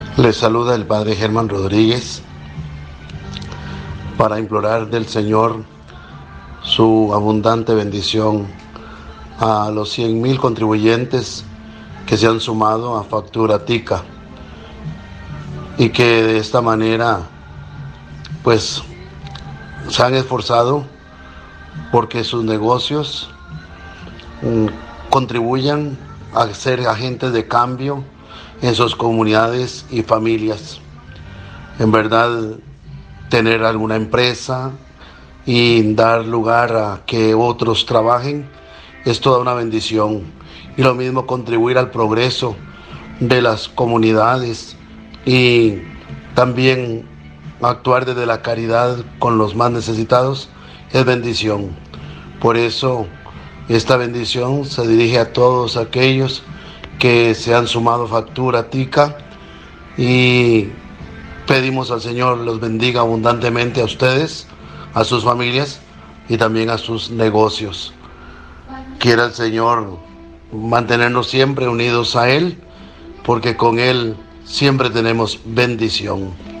Sacerdote católico